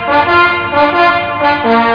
1 channel
bugleca.mp3